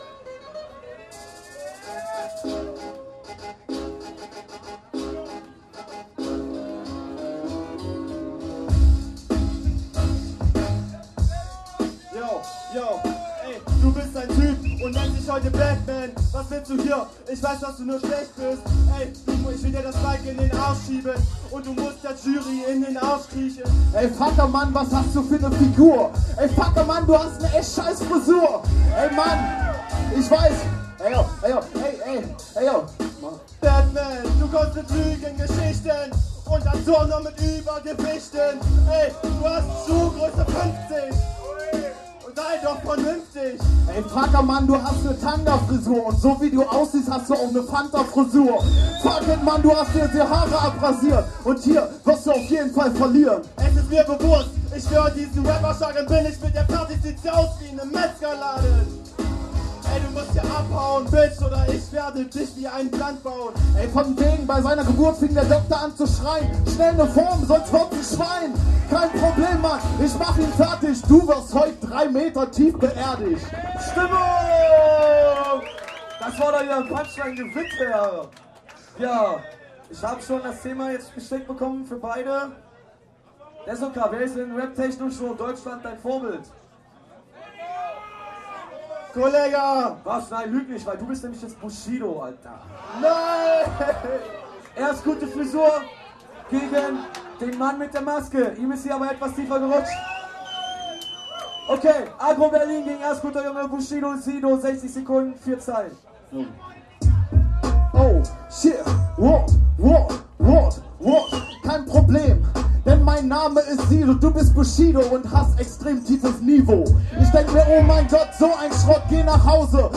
Das Viertelfinale: